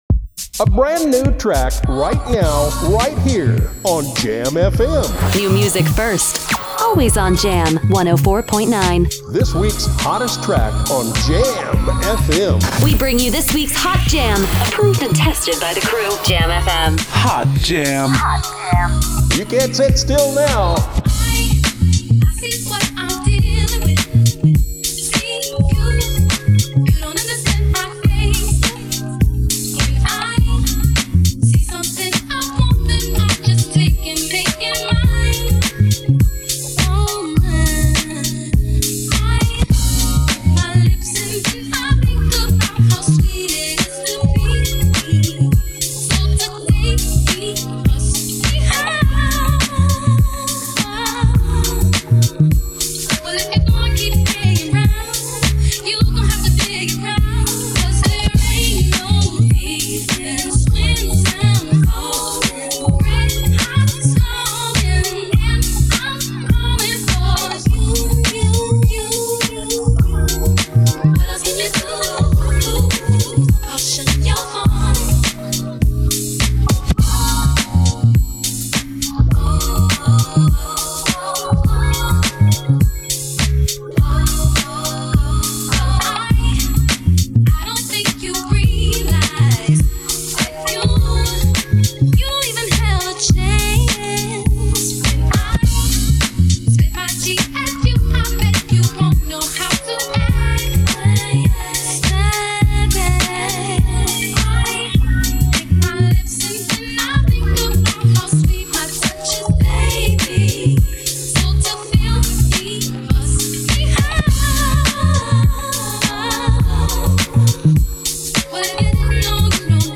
techno aan de donkere zijde van het muzikale spectrum
waar het bekendstaat om zijn soepele en funky karakter.